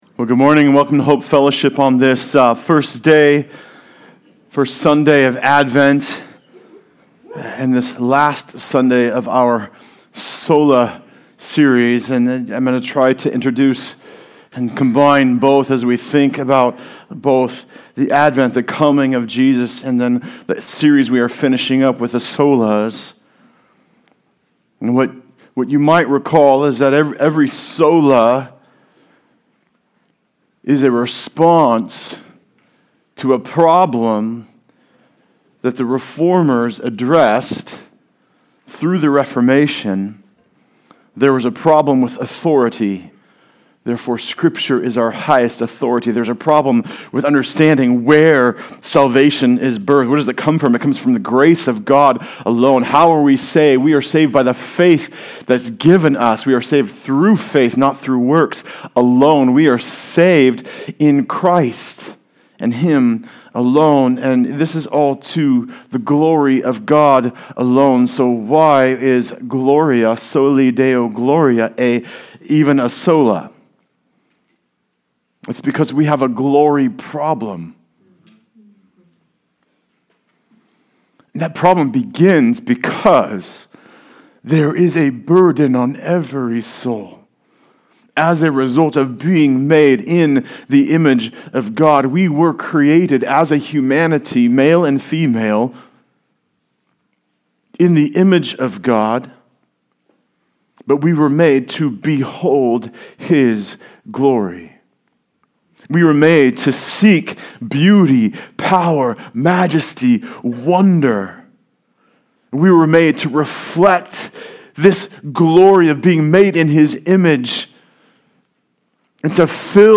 The Solas Service Type: Sunday Service Related « Solus Christus